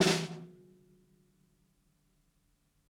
ROOMY_SNARE_BUZZ.wav